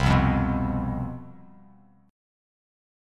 CM7sus4 chord